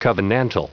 Prononciation du mot covenantal en anglais (fichier audio)
Prononciation du mot : covenantal